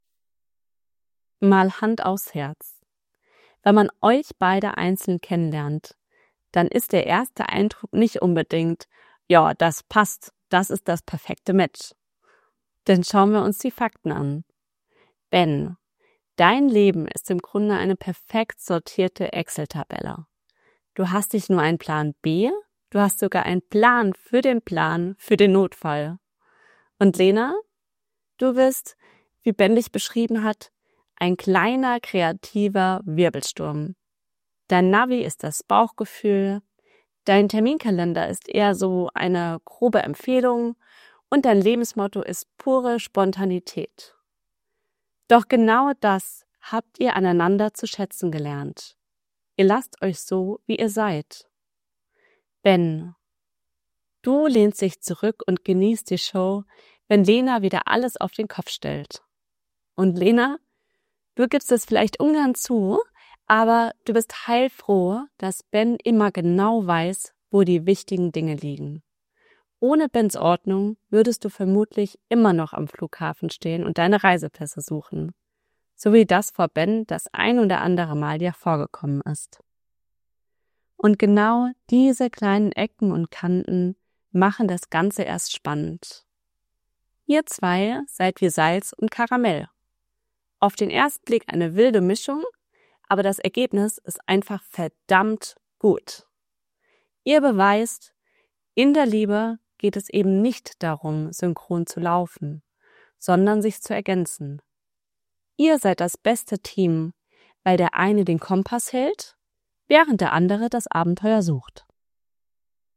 Humorvoll & Locker
Charmant, ehrlich und garantiert ohne Kitsch.
hoerprobe-traurede-humorvoll-locker-mkk-speechdesign.mp3